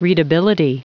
Prononciation du mot readability en anglais (fichier audio)
Prononciation du mot : readability